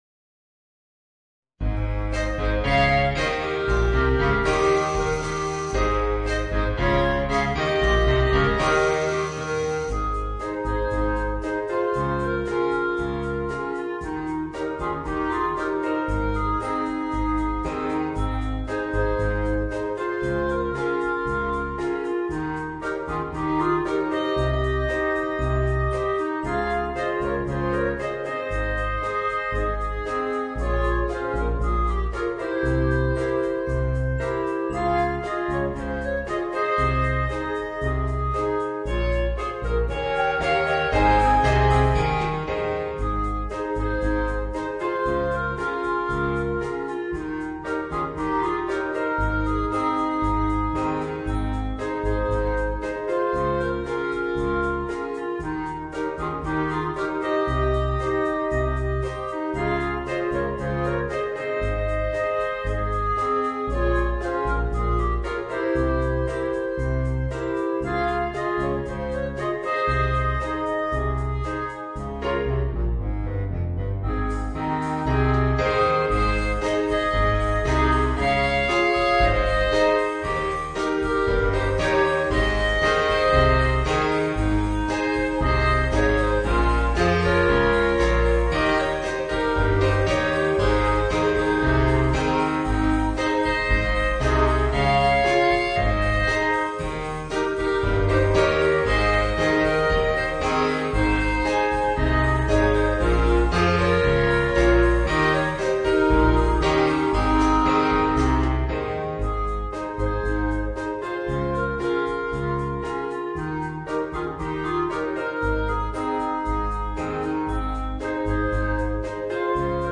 Voicing: 4 Clarinets and Rhythm Section